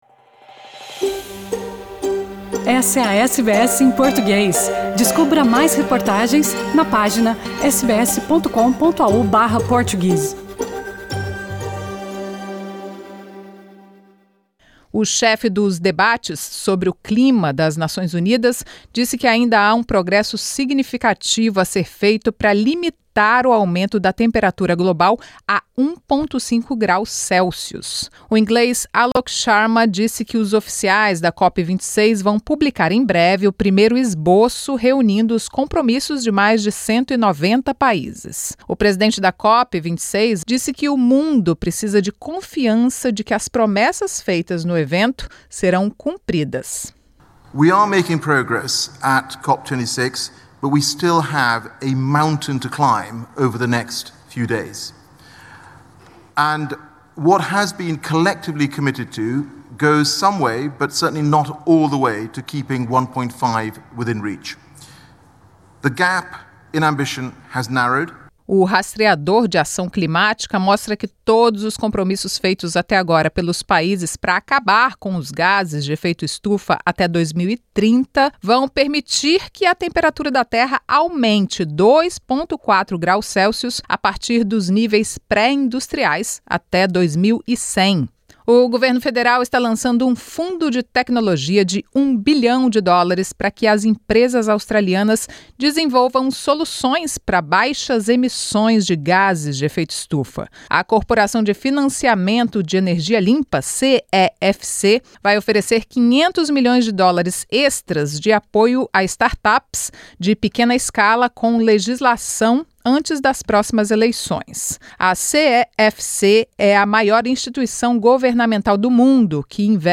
Preso homem mais procurado da Austrália por envolvimento em tráfico de drogas. As notícias da Austrália e do Mundo da Rádio SBS para esta quarta-feira.